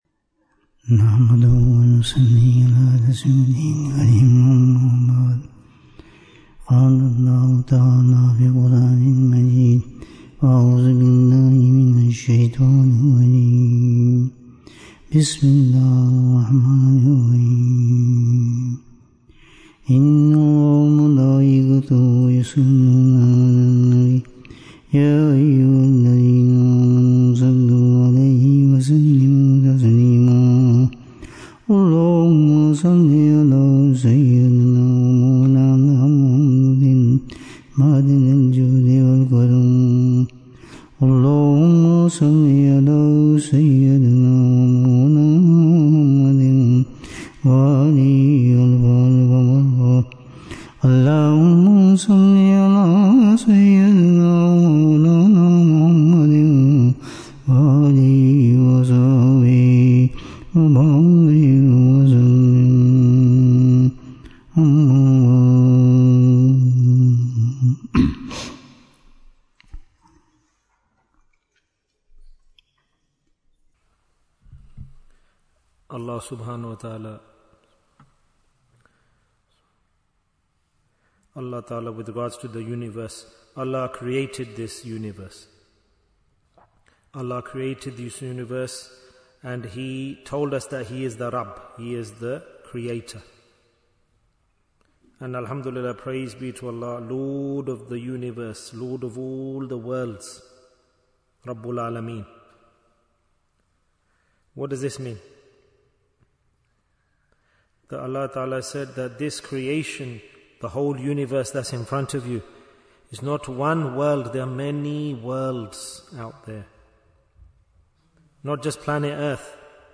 The Importance of Dua Bayan, 70 minutes9th April, 2026